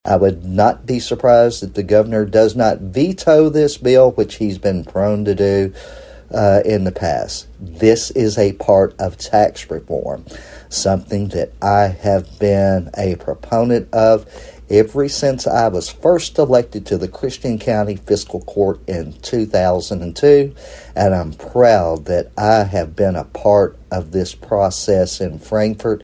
click to download audioNinth District State Representative Myron Dossett says he is pleased to see tax reform continuing to be a priority for the Kentucky General Assembly, but is hopeful Governor Andy Beshear will concur.